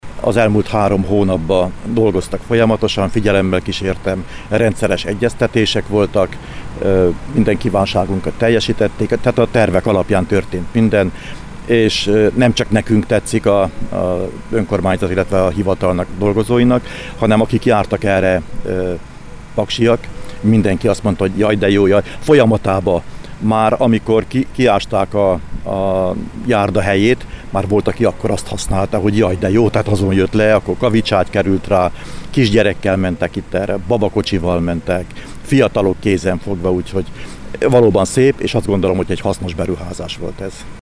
A sajtótájékoztatóval egybekötött műszaki átadáson Mezősi Árpád, a körzet önkormányzati képviselője beszélt a projekt részleteiről.